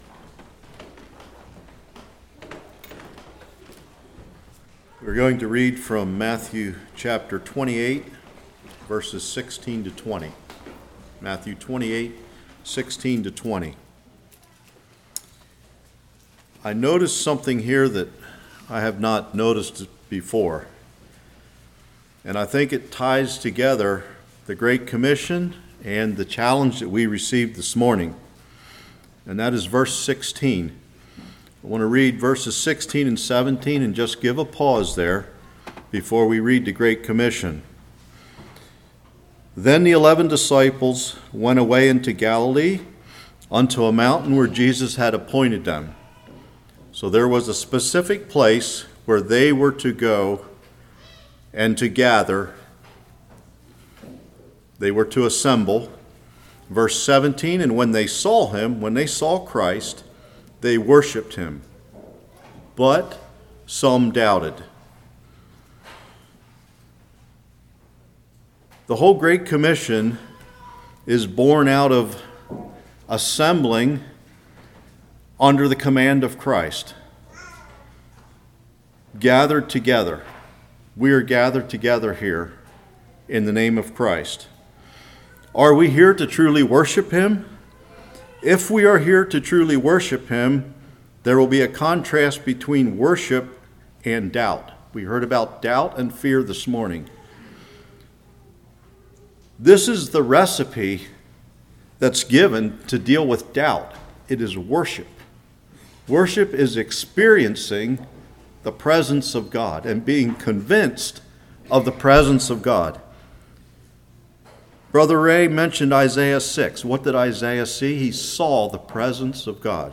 Matthew 28:16-20 Service Type: Morning Come and see